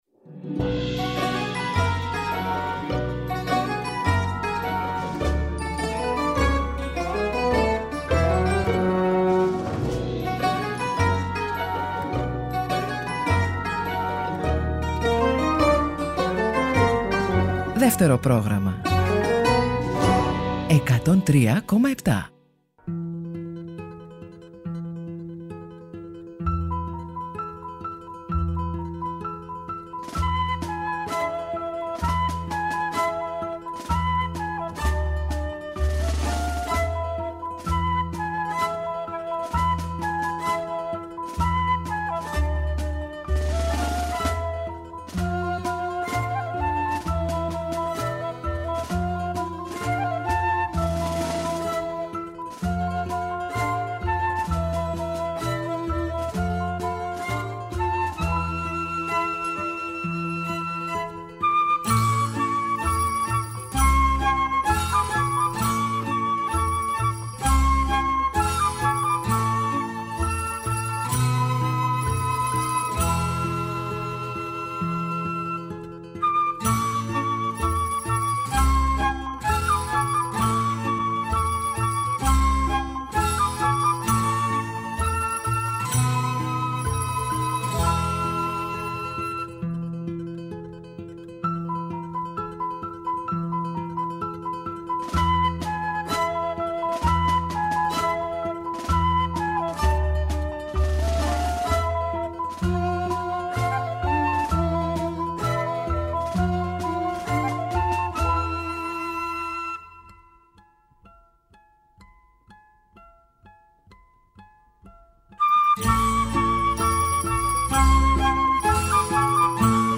τον δίσκο που ηχογραφήθηκε με τραγούδια από τις παραστάσεις